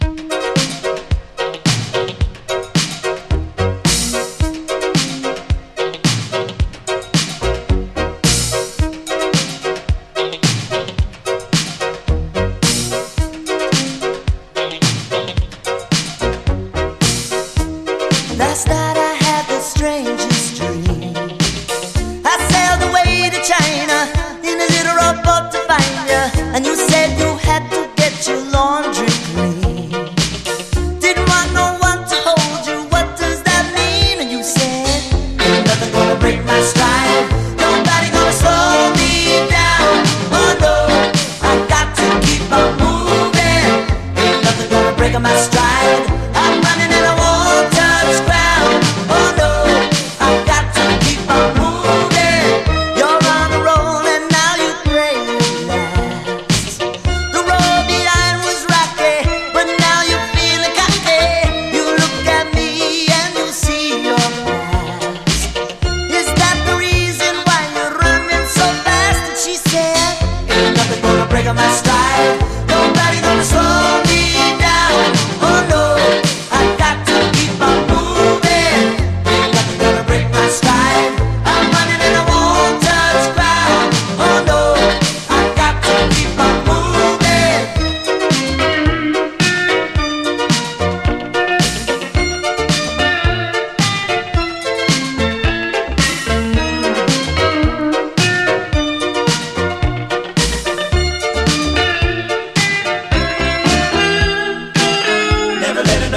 DISCO, 80's～ ROCK, ROCK, FUNK-A-LATINA, 7INCH
ファンカラティーナ〜レゲエ風味のトロピカルな80’Sヒット！
爽やかなシンセ・サウンドとキャッチーなメロディーがプール・サイドに映えるトロピカル・ダンサー！